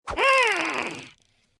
avatar_emotion_angry.ogg